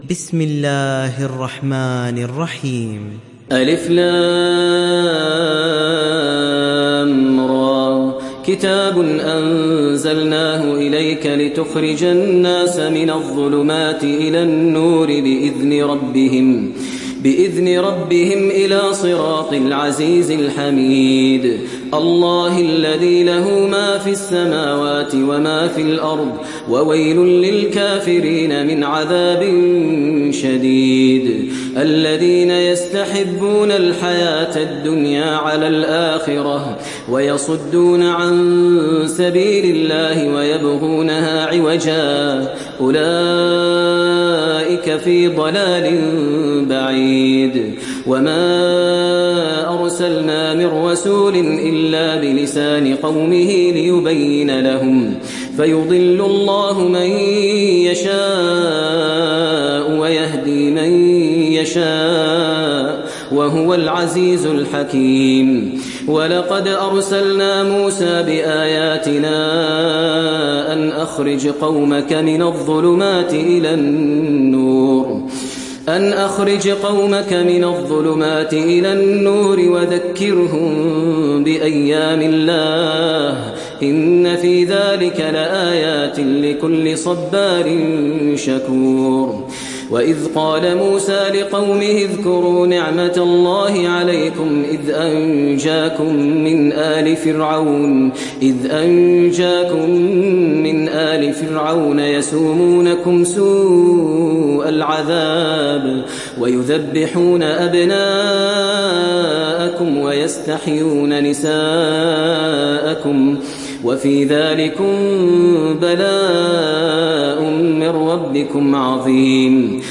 Surat Ibrahim mp3 Download Maher Al Muaiqly (Riwayat Hafs)